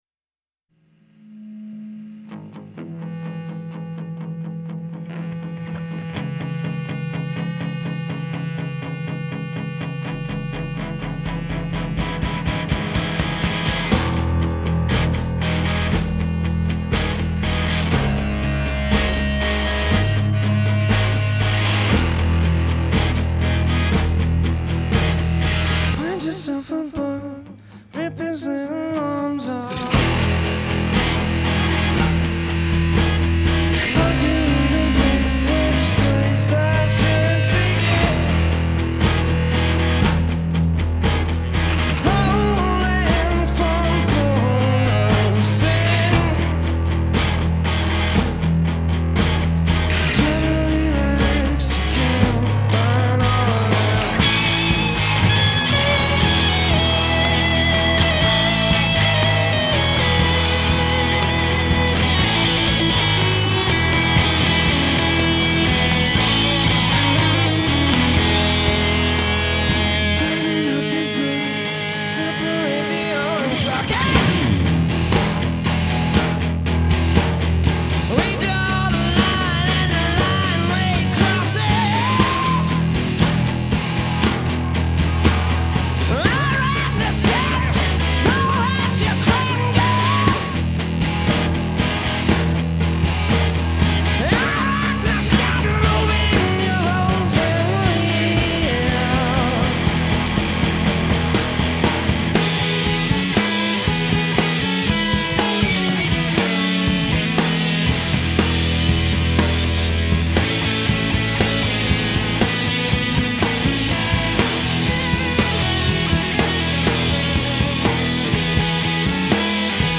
Vocals, Guitar
Bass
Drums